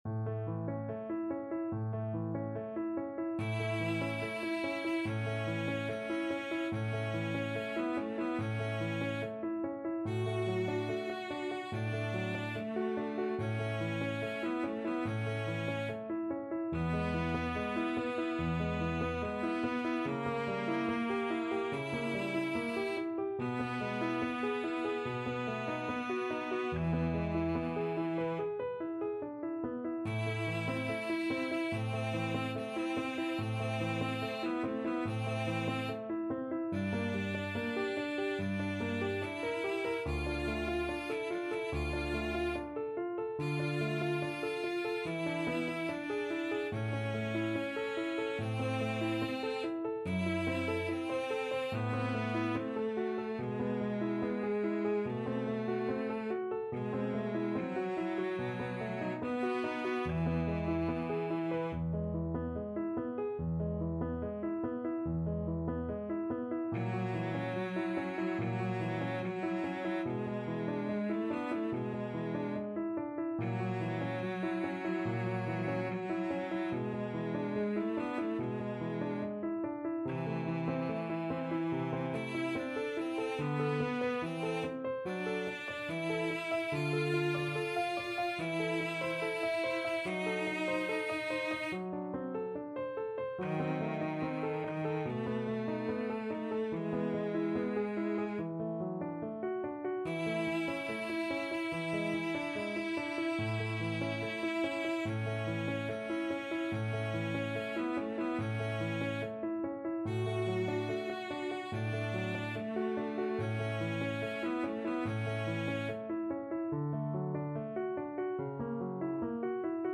Andante =72
Cello Duet  (View more Intermediate Cello Duet Music)
Classical (View more Classical Cello Duet Music)